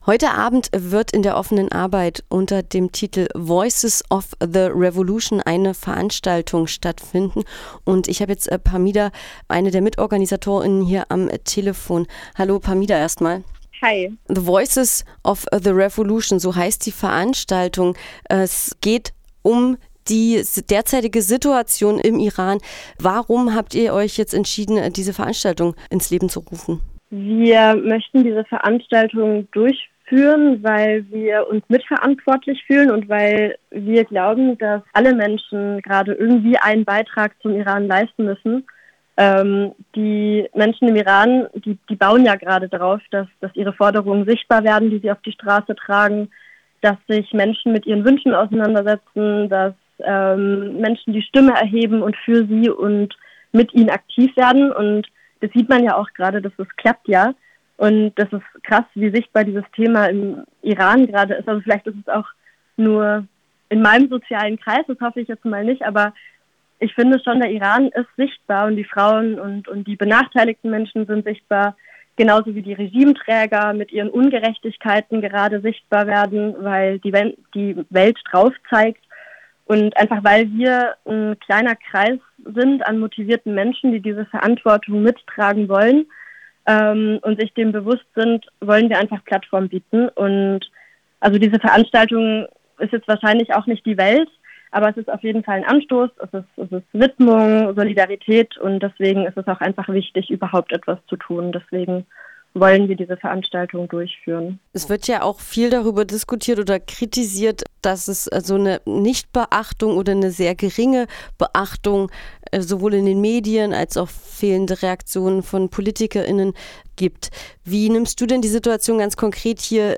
Radio F.R.E.I. sprach im Vorfeld mit einer Organisatorin über die Hintergründe des Info-Abends.